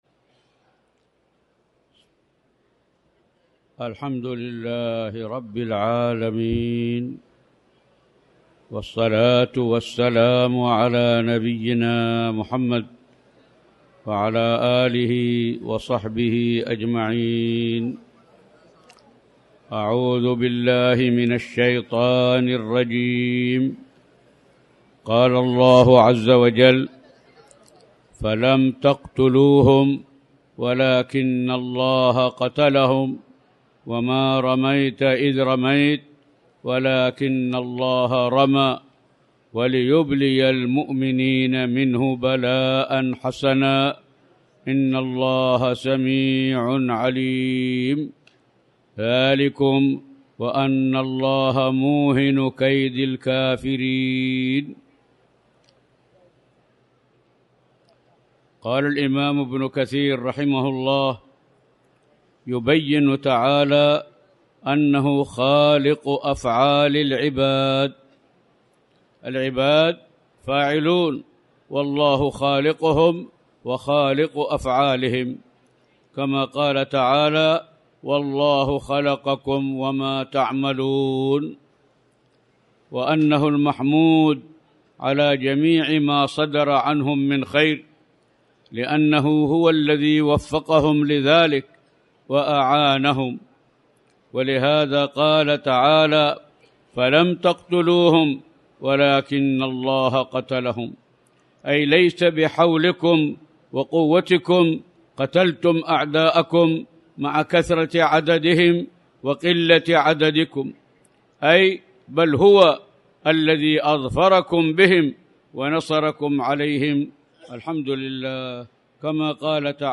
تاريخ النشر ١٨ شعبان ١٤٣٩ هـ المكان: المسجد الحرام الشيخ